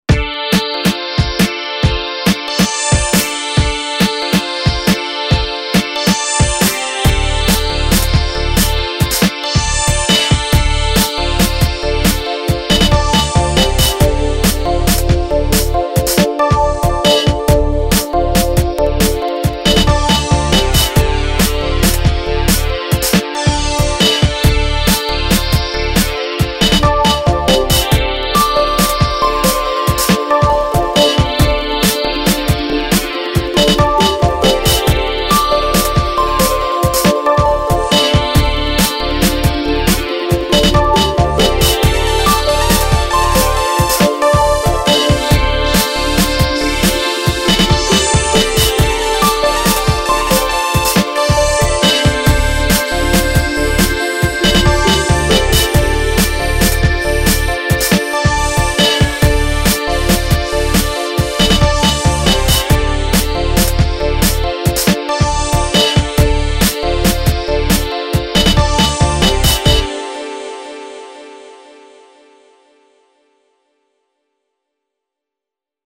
Demonstration of Soup's morphing feature